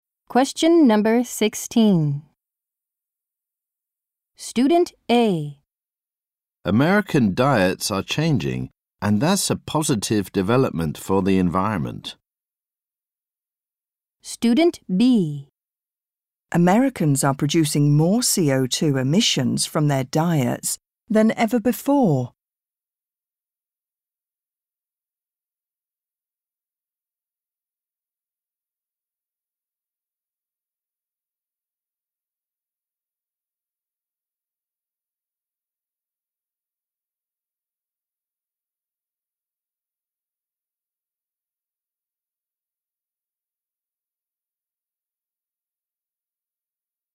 ○共通テストの出題音声の大半を占める米英の話者の発話に慣れることを第一と考え，音声はアメリカ（北米）英語とイギリス英語で収録。
（新）第5問形式：【第14回】第3問　問11～15 （アメリカ（北米）英語）